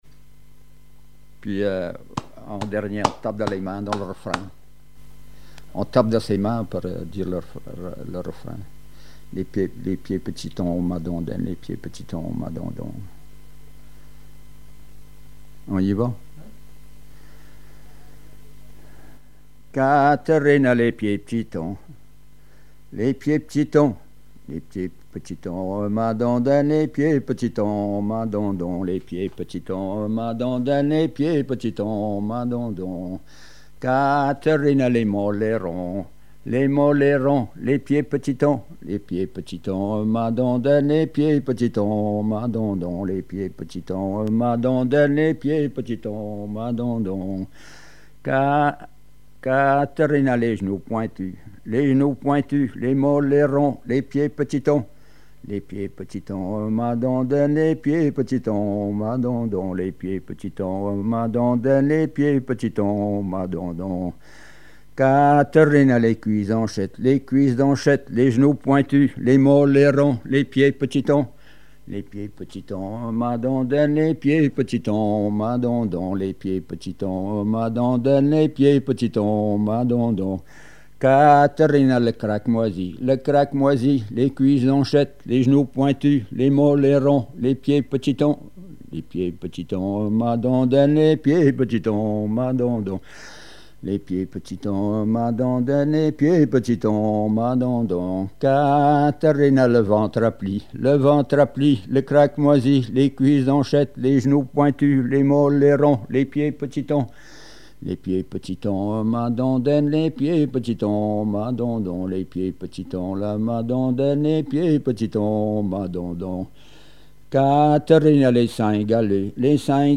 Mémoires et Patrimoines vivants - RaddO est une base de données d'archives iconographiques et sonores.
Genre énumérative
Répertoire de chansons traditionnelles et populaires
Pièce musicale inédite